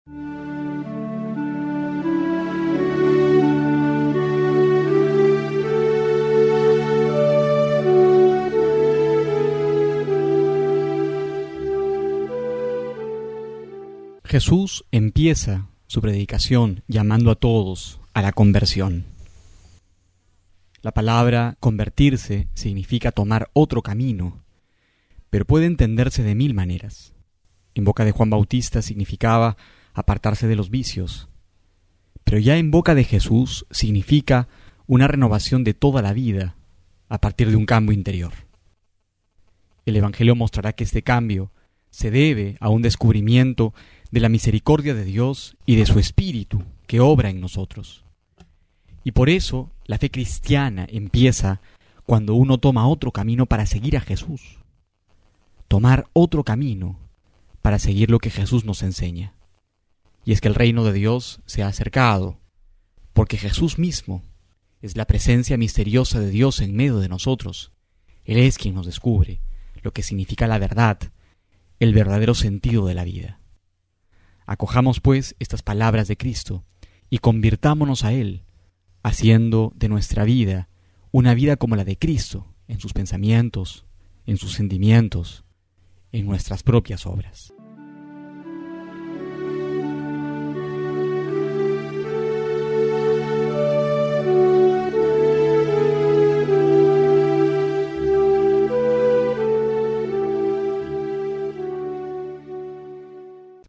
enero07-08homilia.mp3